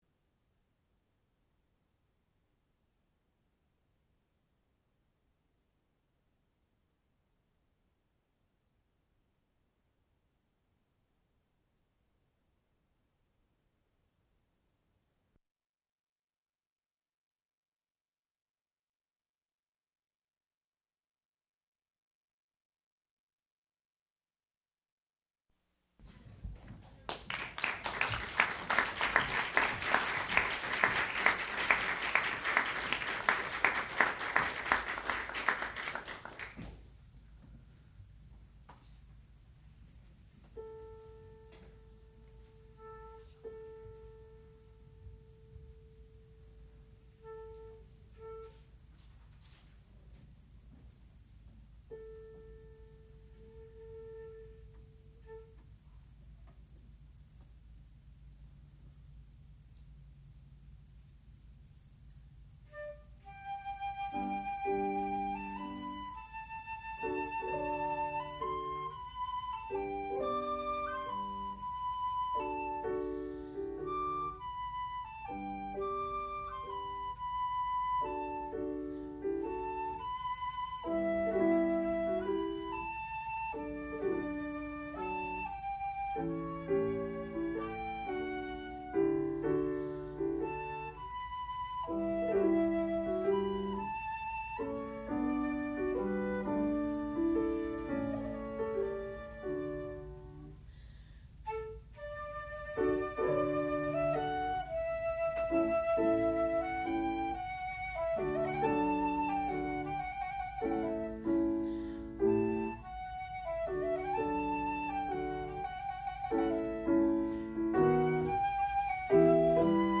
sound recording-musical
classical music
flute
harpsichord
Master's Recital